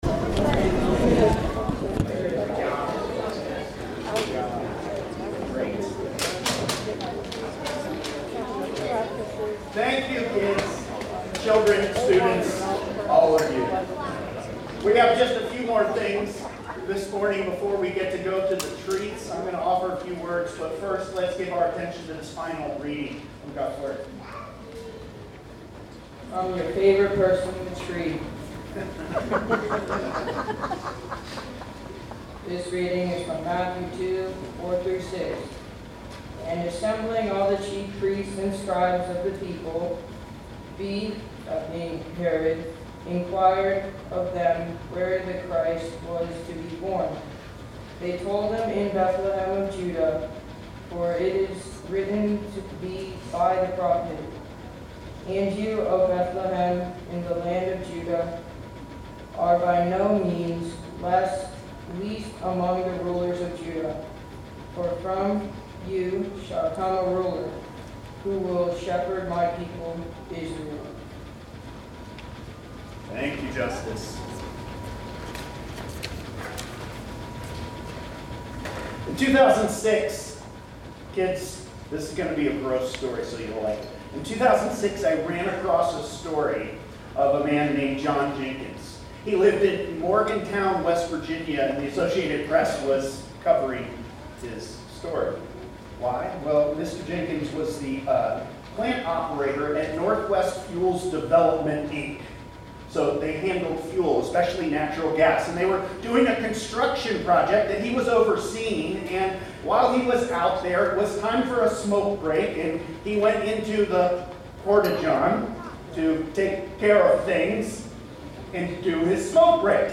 Sermons | Hope Presbyterian Church of Crozet